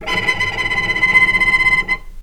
Strings / cello / tremolo / vc_trm-C6-pp.aif
vc_trm-C6-pp.aif